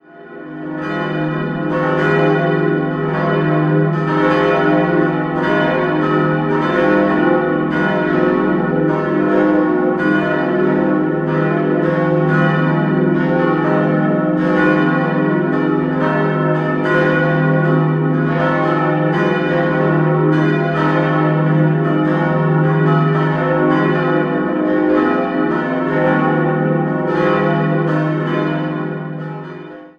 Idealquartett c'-es'-f'-as' Die Glocken wurden 1924 vom Bochumer Verein für Gussstahlfabrikation gegossen.